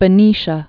(bə-nēshə)